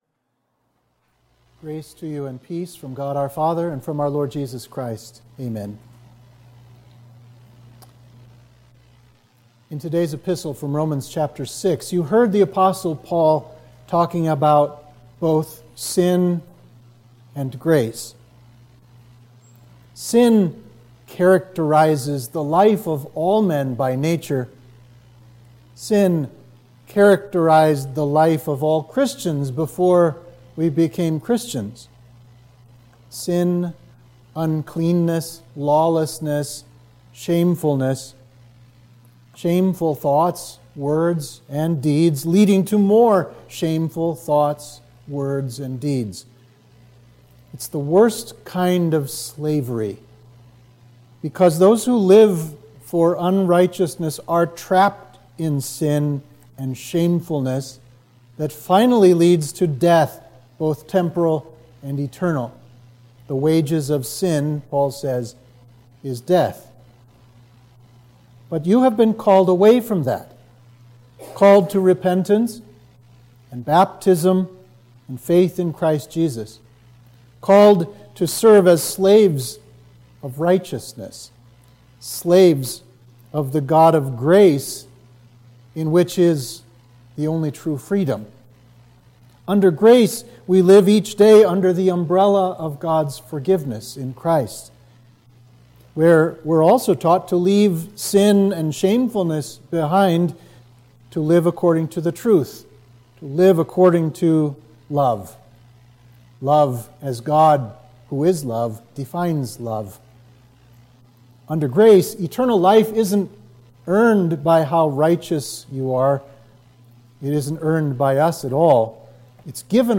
Sermon for Trinity 7